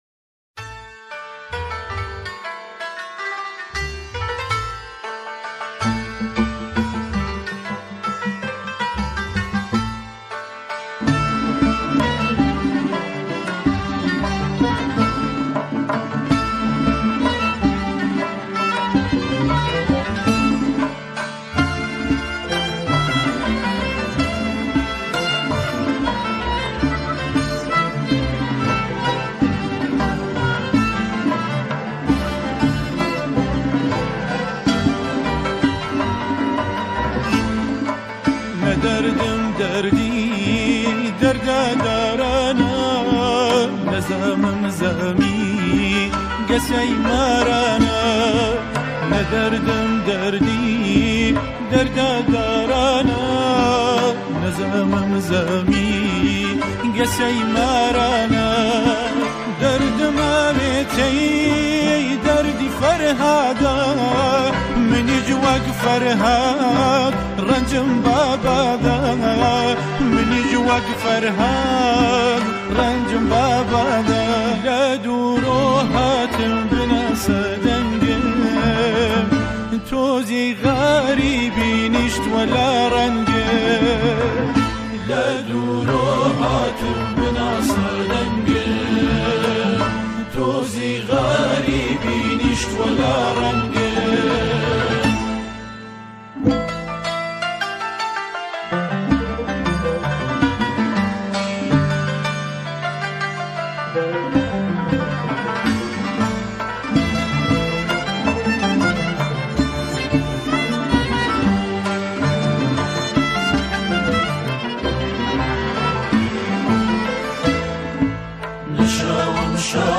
همخوانی شعری به گویش کردی
گروه کر